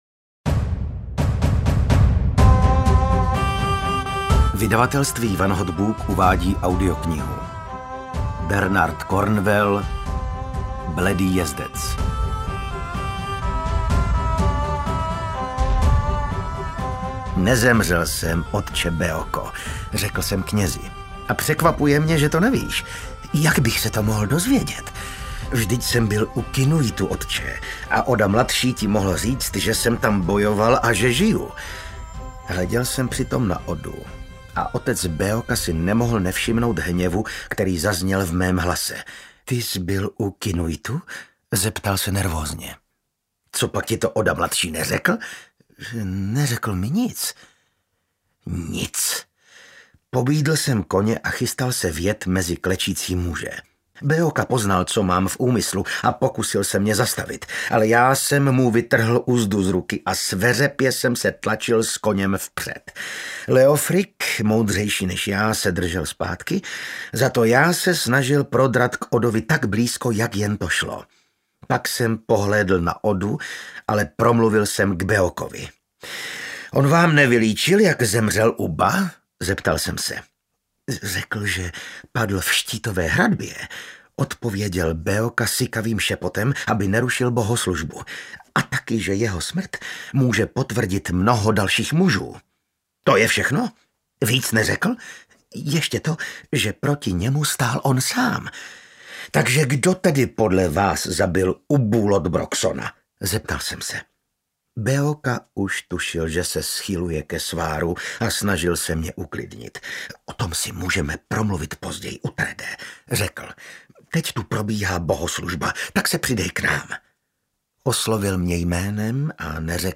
Bledý jezdec audiokniha
Ukázka z knihy